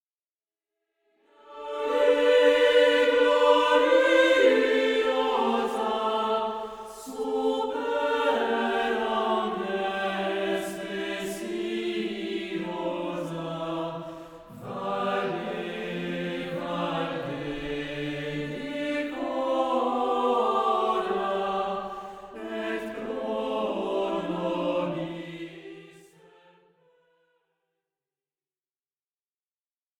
Motet marial